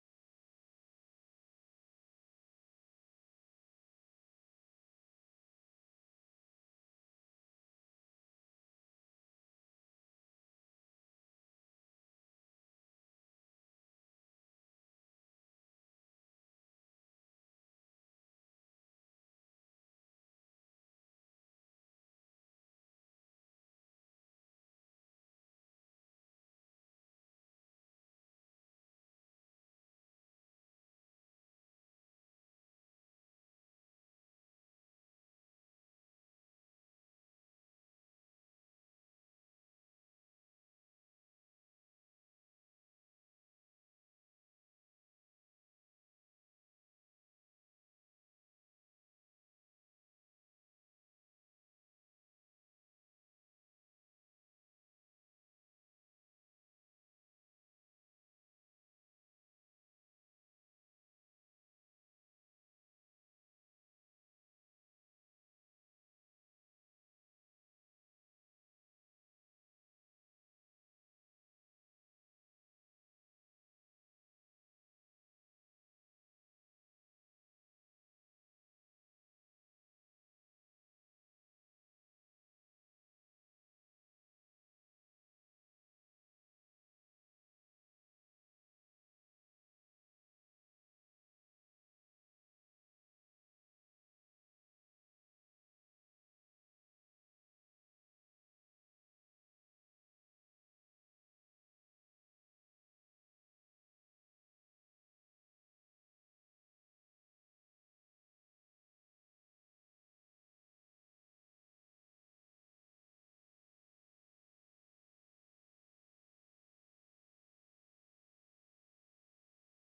Hybrid Meeting (Virtual Meeting Platform / Committee Room M46, Ground Floor, Marks Building)